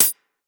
UHH_ElectroHatB_Hit-12.wav